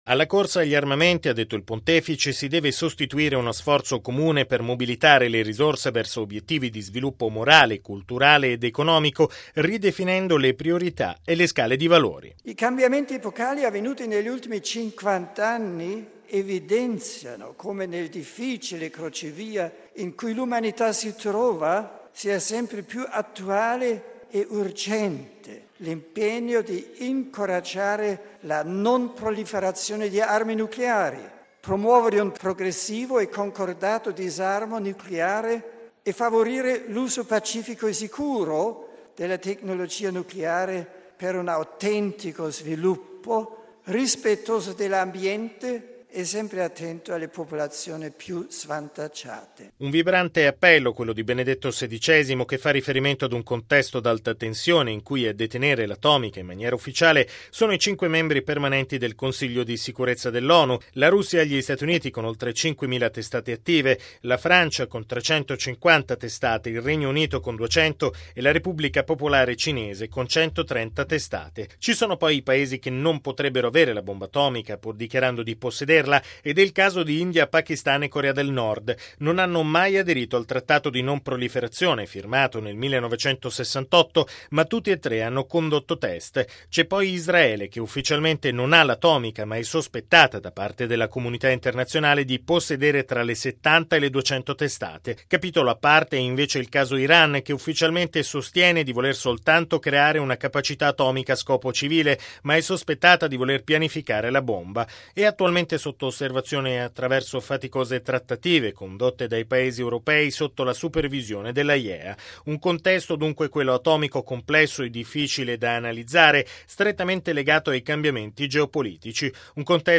◊   Vasta eco hanno suscitato le parole di Benedetto XVI, che ieri prima della recita dell’Angelus, ha ricordato il cinquantesimo anniversario dell'AIEA, l'Agenzia Internazionale per l'Energia Atomica, l'organismo dell'ONU che vigila contro il proliferare delle armi nucleari.